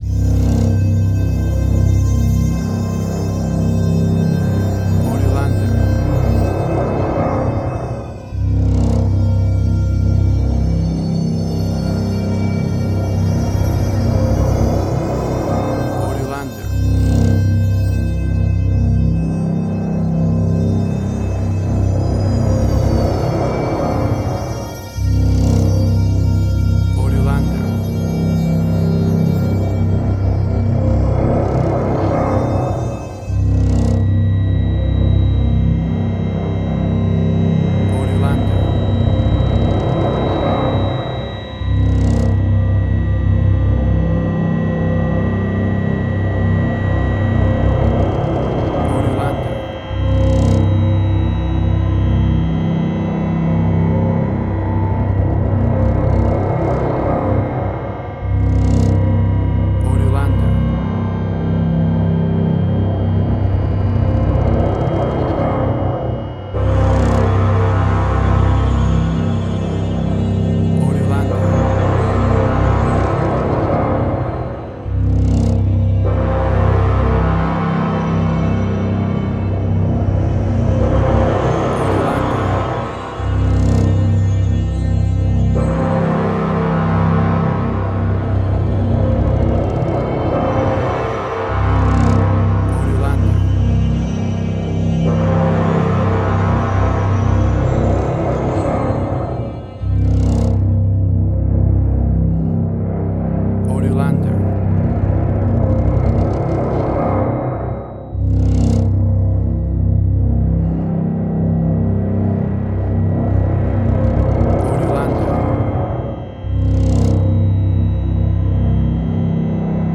Soundscapes
WAV Sample Rate: 16-Bit stereo, 44.1 kHz